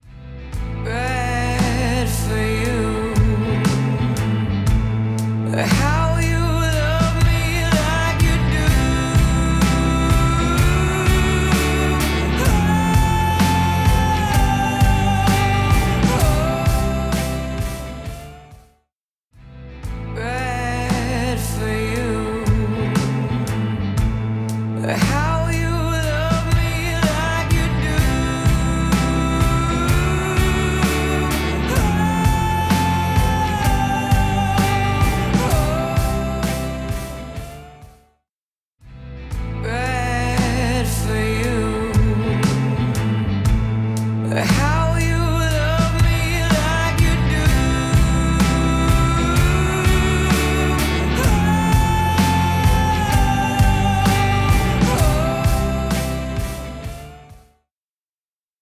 Invigorate | Full Mix | Preset: Treble Lifter Exciter
Invigorate-Full-Mix-Treble-Lifter-Exciter-CB.mp3